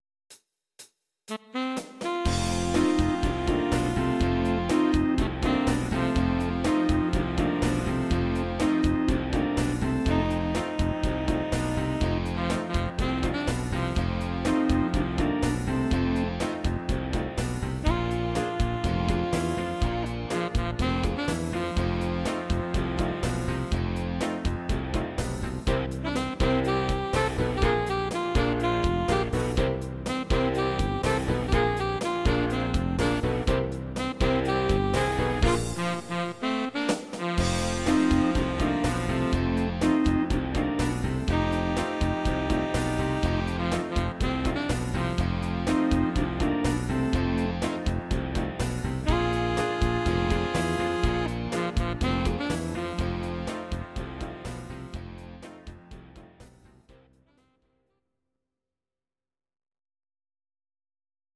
These are MP3 versions of our MIDI file catalogue.
Please note: no vocals and no karaoke included.
instr. Saxophon